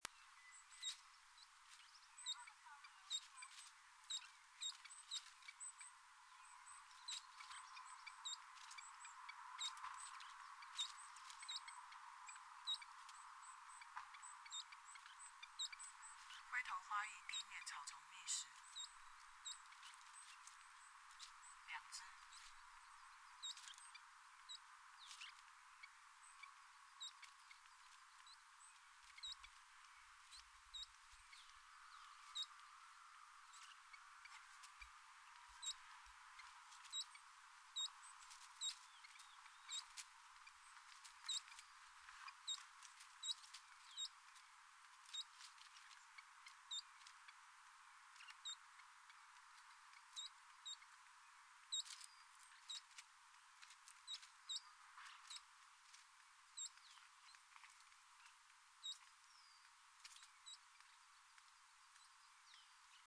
29-3東埔2012mar26灰頭花翼地面覓食叫1.mp3
紋喉雀鶥 Alcippe cinereiceps formosana
錄音地點 南投縣 信義鄉 東埔
錄音環境 草叢
行為描述 兩隻覓食
錄音: 廠牌 Denon Portable IC Recorder 型號 DN-F20R 收音: 廠牌 Sennheiser 型號 ME 67